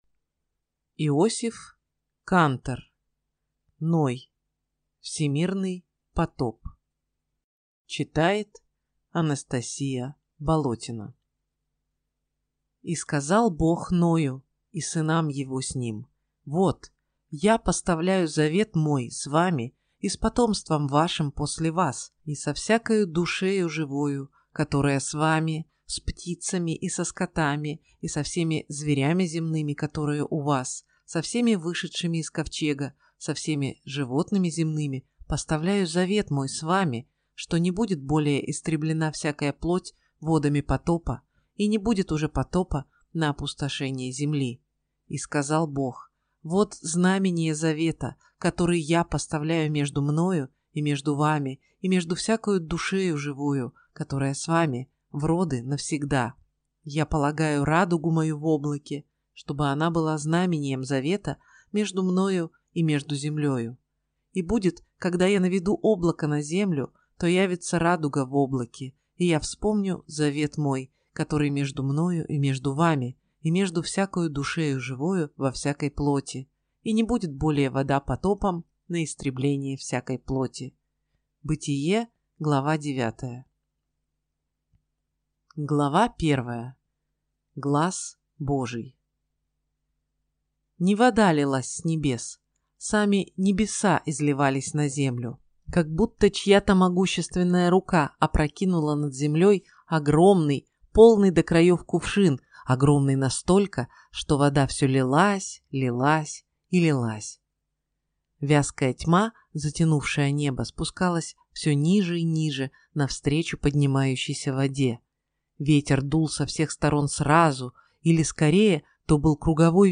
Аудиокнига Ной. Всемирный потоп | Библиотека аудиокниг